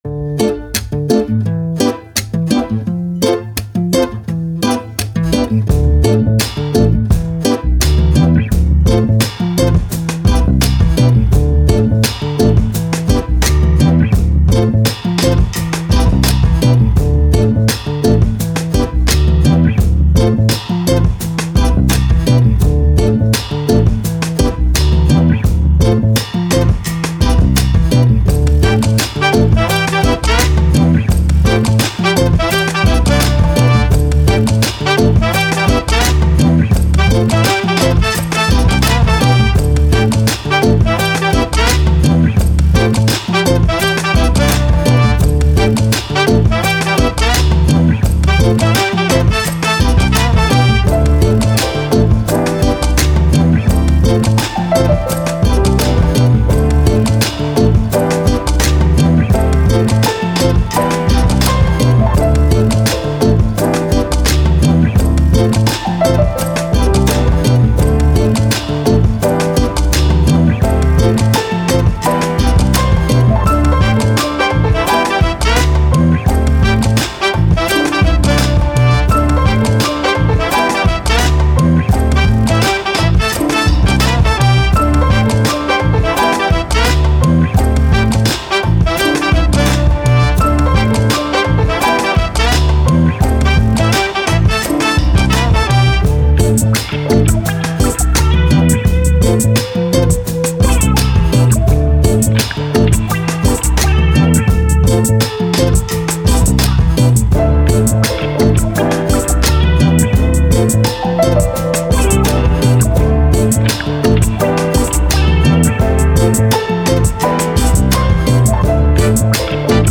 Hip Hop, Soul, Pop, Confident, Positive, Story